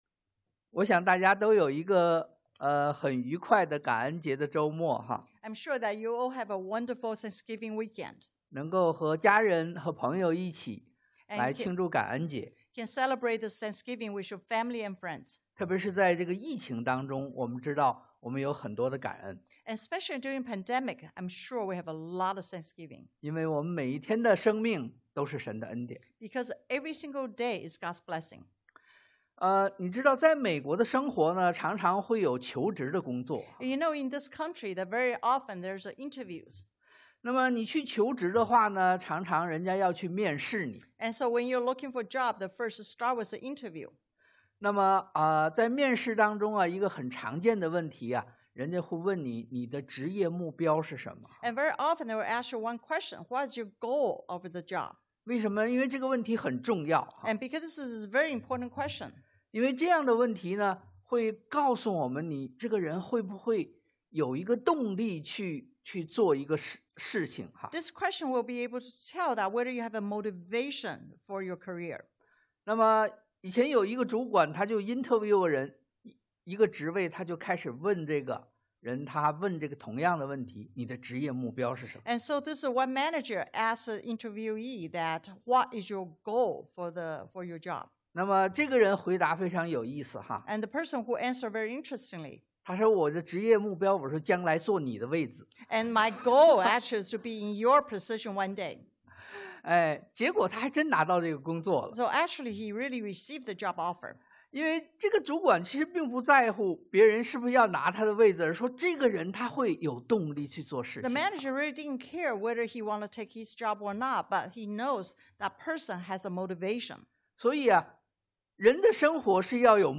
創 Gen 21:1-21 Service Type: Sunday AM 擁抱未來 Embrace Your Destiny 經文Passage